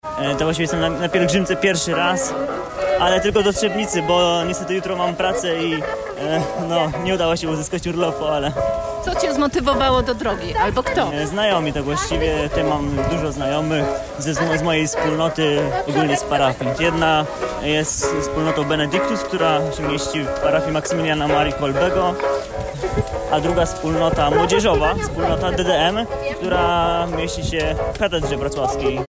w tym roku pielgrzymuje po raz pierwszy.